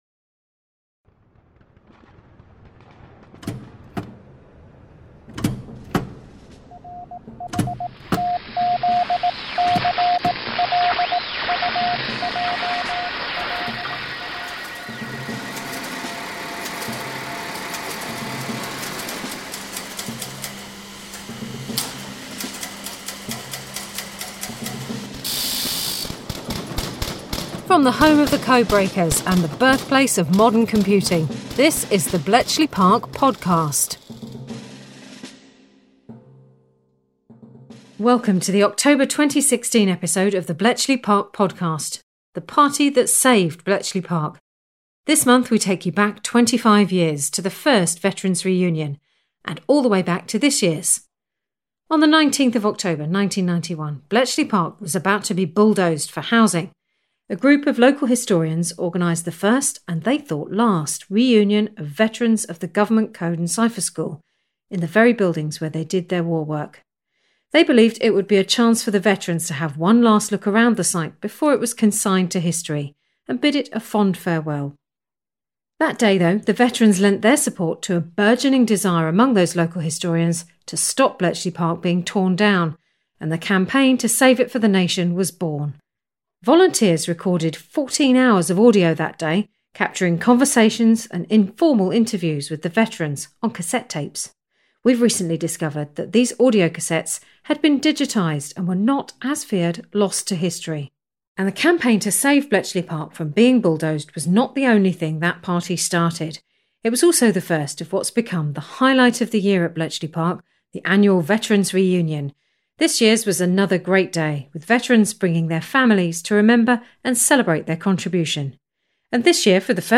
Volunteers recorded 14 hours of audio that day, capturing conversations and informal interviews with the Veterans on cassette tapes. We’ve recently discovered that these audio cassettes had been digitised and were not, as feared, lost to history.